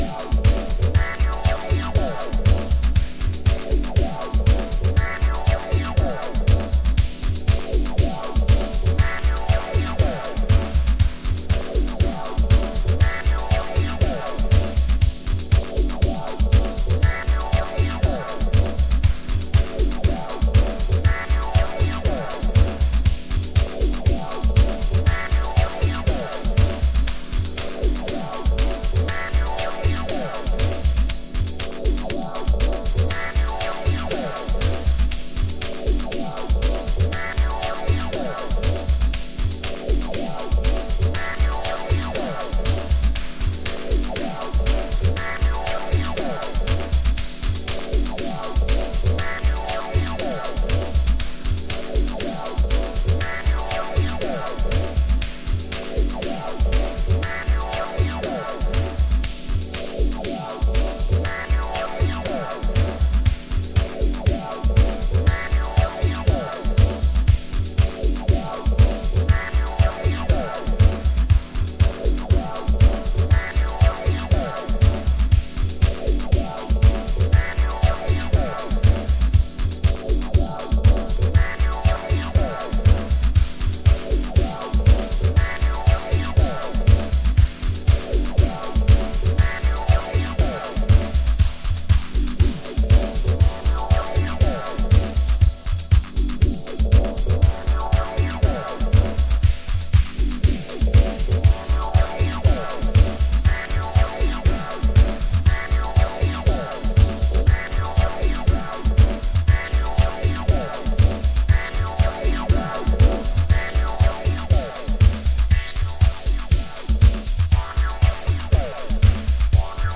House / Techno
707と催眠シンセがじわじわと空間を包み込むヒプノハウス